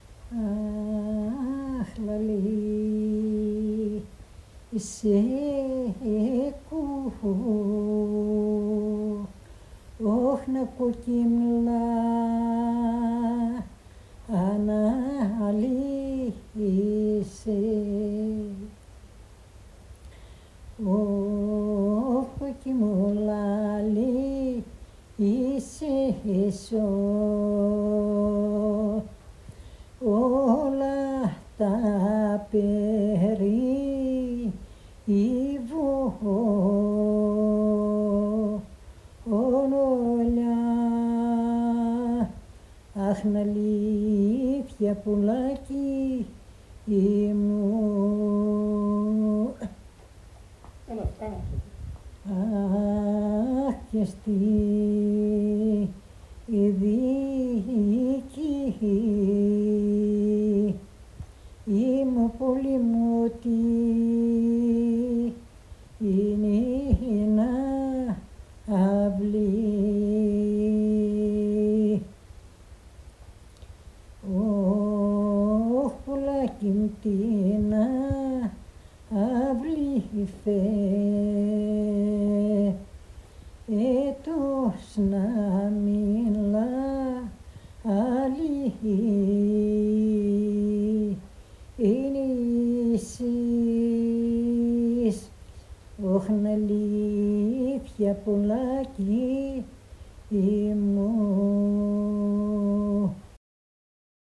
Επιτόπια έρευνα σε κοινότητες των Σαρακατσάνων της περιοχής Σερρών για τον εντοπισμό του τραγουδιστικού τους ρεπερτορίου